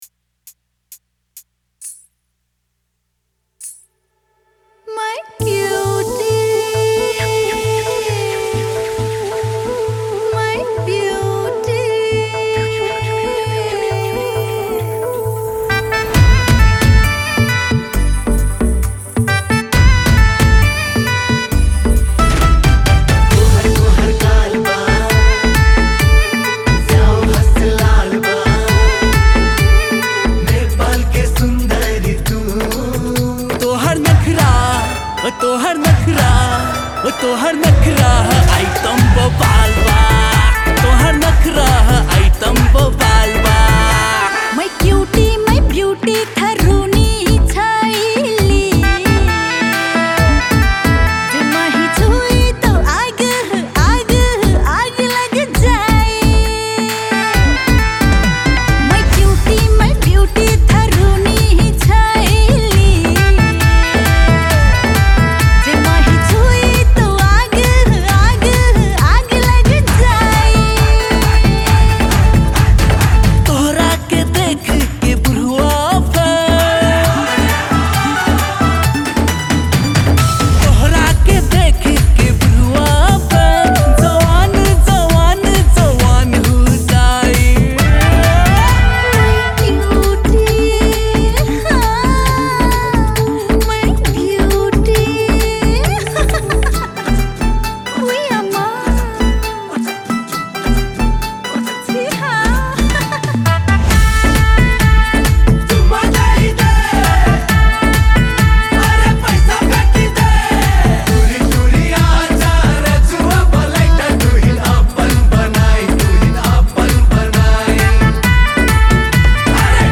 Tharu Item Dancing Song